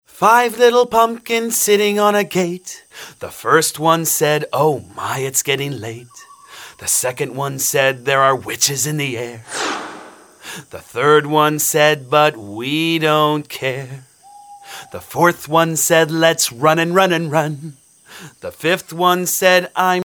jazzy